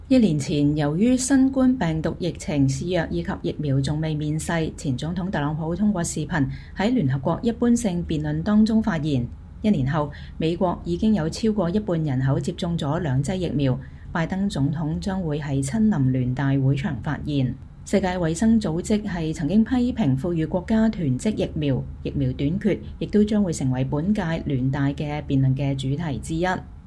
拜登總統在白宮南院禮堂向主要經濟體能源和氣候論壇的與會者發表講話，國務卿布林肯在旁聆聽。